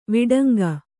♪ viḍanga